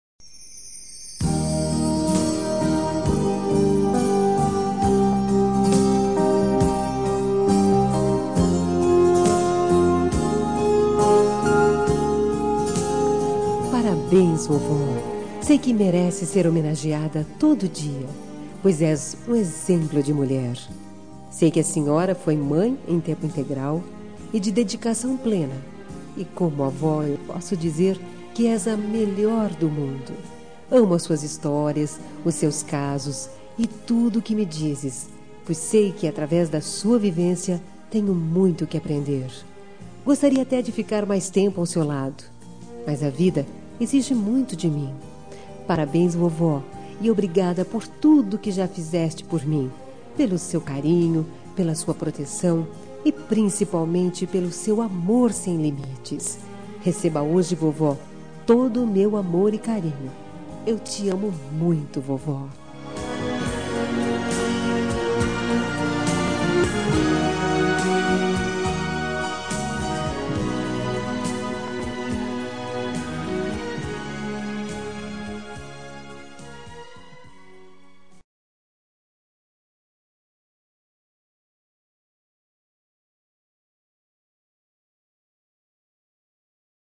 Telemensagem Aniversário de Avó – Voz Feminina – Cód: 2057